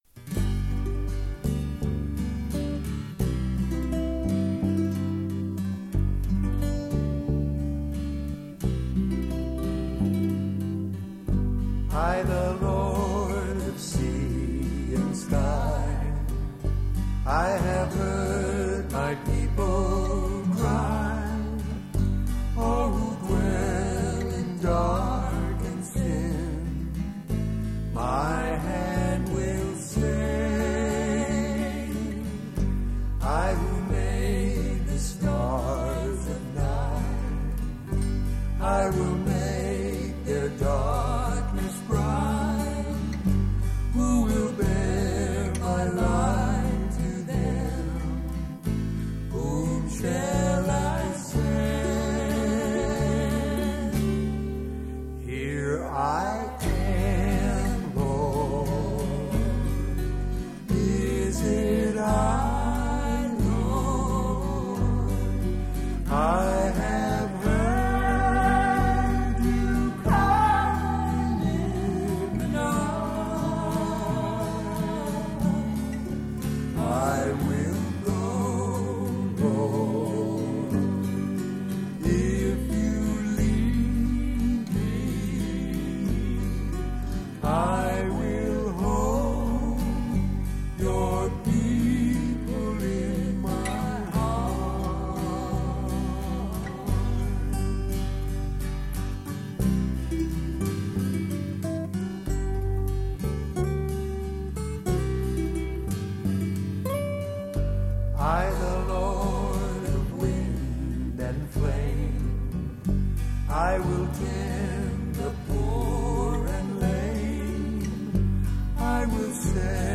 1. Devotional Songs
Major (Shankarabharanam / Bilawal)
8 Beat / Keherwa / Adi
Medium Slow
5 Pancham / G
2 Pancham / D